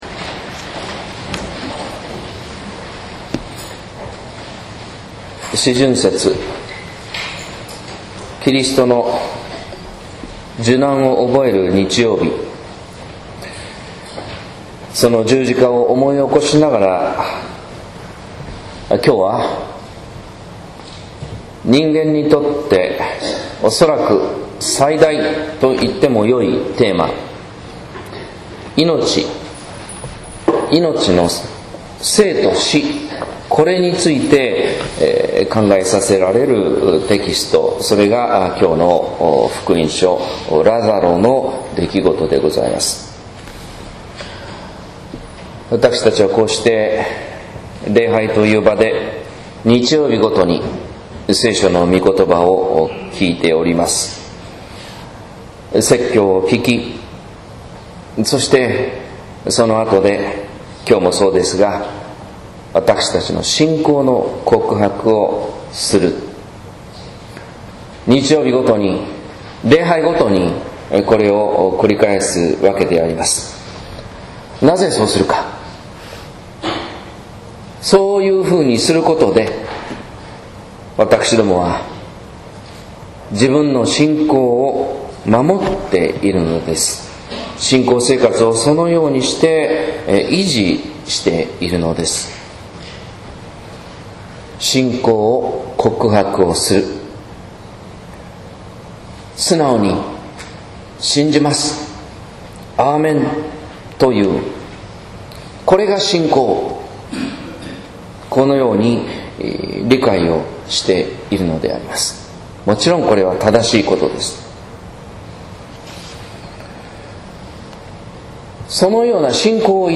説教「信仰が現実になるとき」（音声版）